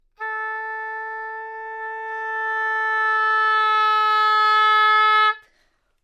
大提琴单音（弹得不好） " 大提琴A4不好的丰富性
描述：在巴塞罗那Universitat Pompeu Fabra音乐技术集团的goodsounds.org项目的背景下录制。单音乐器声音的Goodsound数据集。 instrument :: cellonote :: Aoctave :: 4midi note :: 57microphone :: neumann U87tuning reference :: 442.0goodsoundsid :: 4570 故意扮演一个富裕的例子
标签： 好声音 单注 多样本 A4 纽曼-U87 大提琴
声道立体声